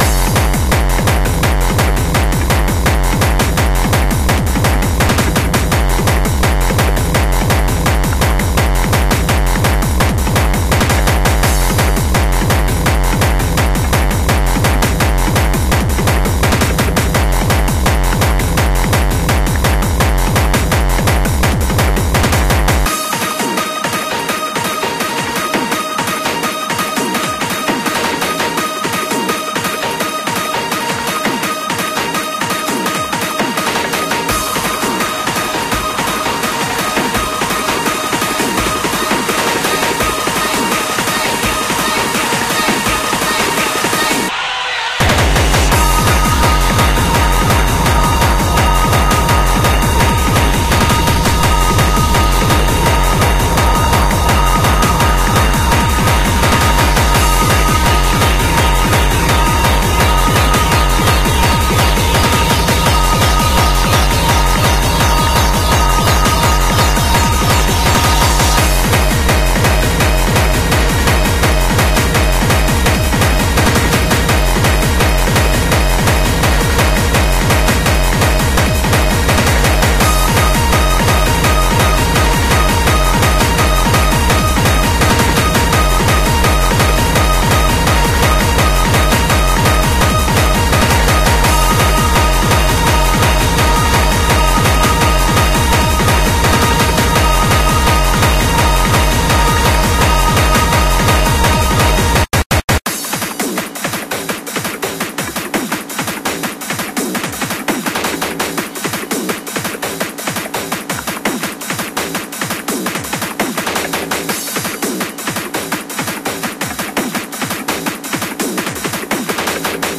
BPM168
Audio QualityMusic Cut